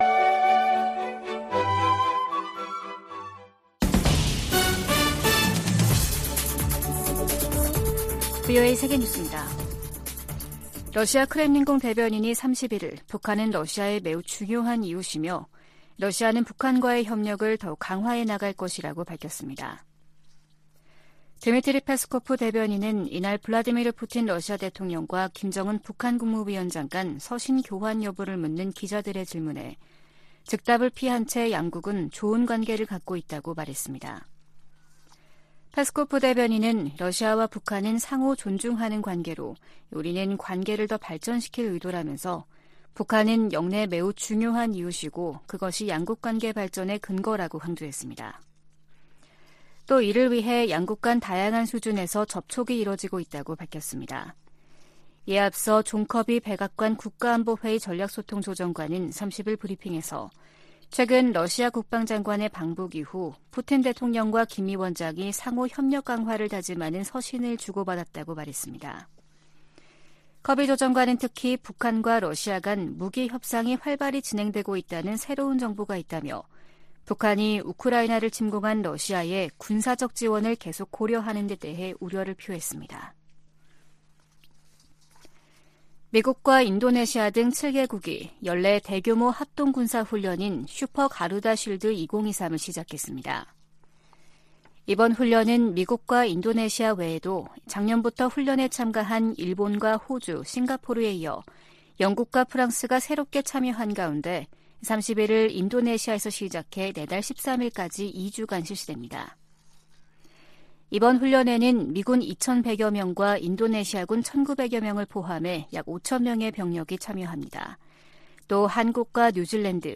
VOA 한국어 아침 뉴스 프로그램 '워싱턴 뉴스 광장' 2023년 9월 1일 방송입니다. 북한이 미한 연합훈련에 반발해 동해상으로 탄도미사일을 발사 했습니다.